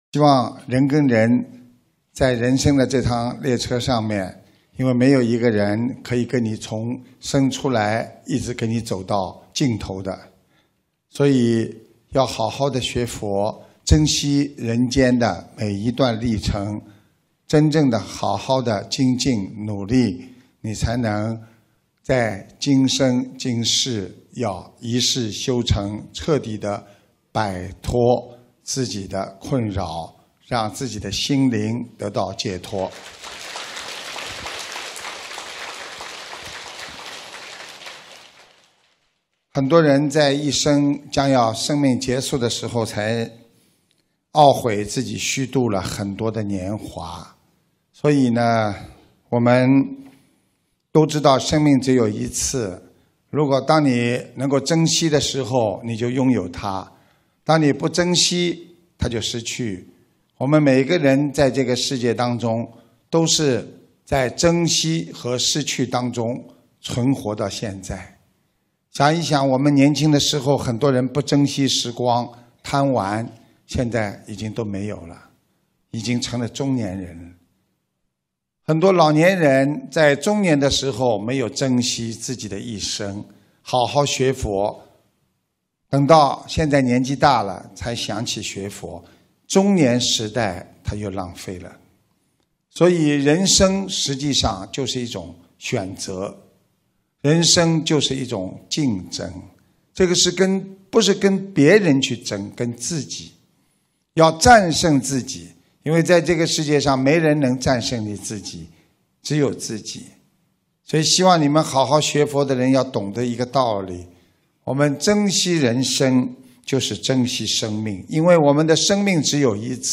音频：人为什么会这么执迷不悟？因为他看不到未来！他每一天就执著现在我要得到！2016年08月18日马来西亚观音堂开光开示